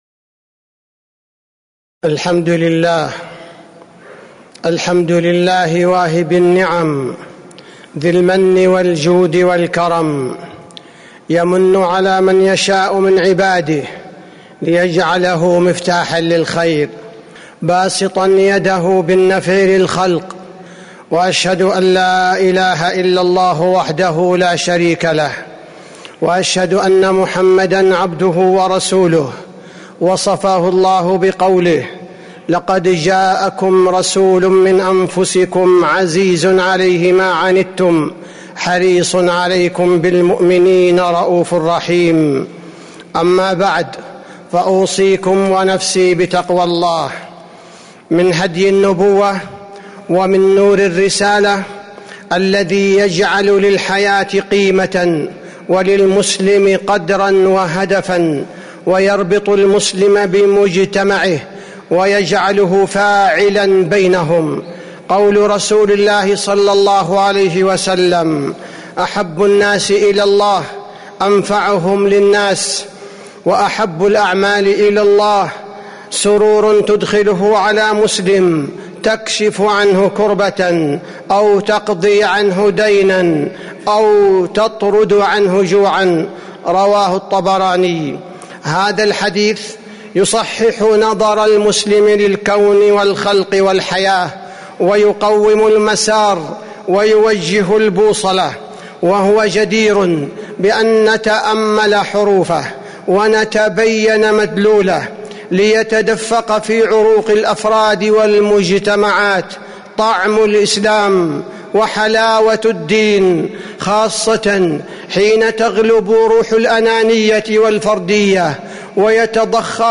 تاريخ النشر ١٩ جمادى الآخرة ١٤٤٦ هـ المكان: المسجد النبوي الشيخ: فضيلة الشيخ عبدالباري الثبيتي فضيلة الشيخ عبدالباري الثبيتي أحب الناس إلى الله أنفعهم